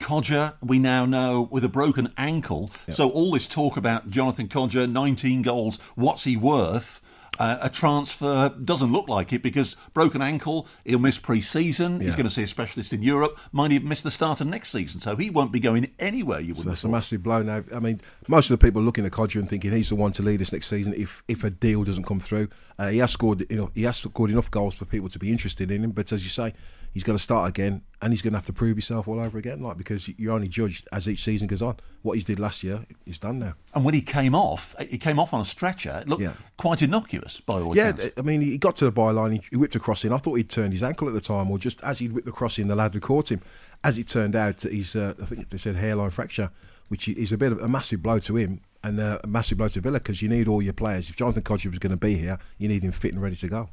Football Phone-In